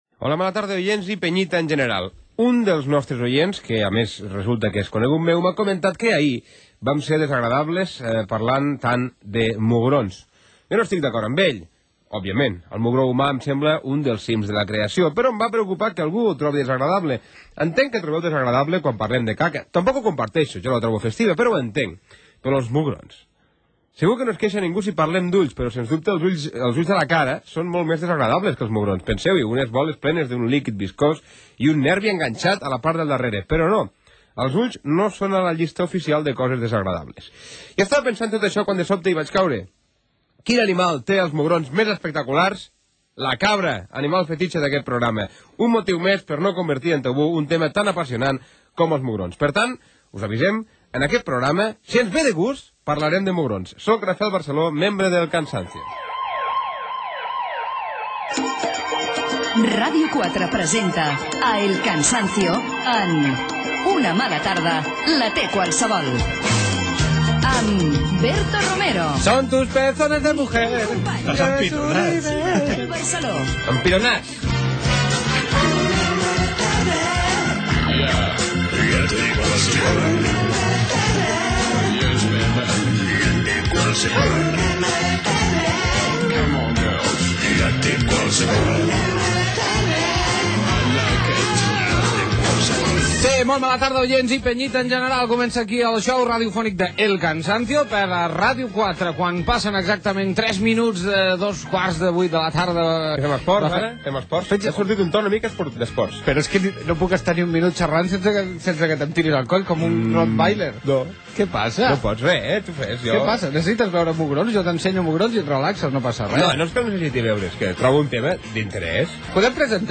careta del programa, hora, salutació, indicatiu
publicitat fictícia Gènere radiofònic Entreteniment